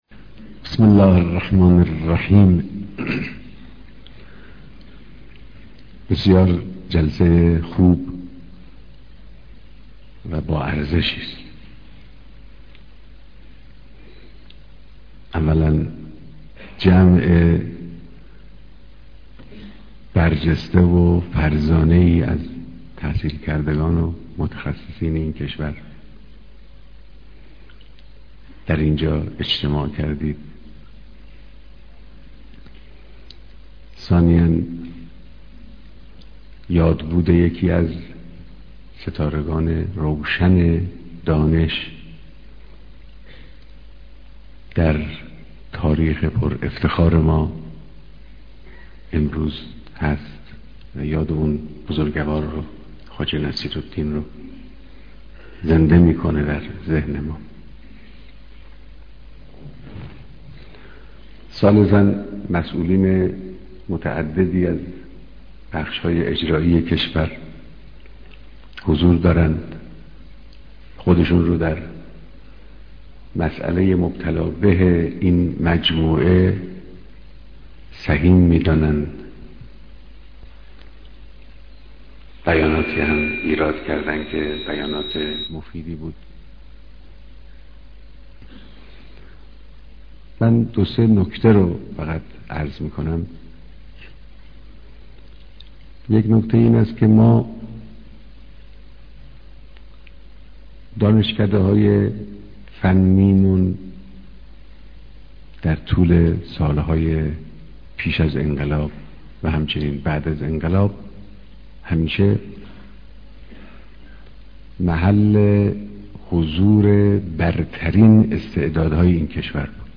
بيانات در ديدار جامعهى مهندسين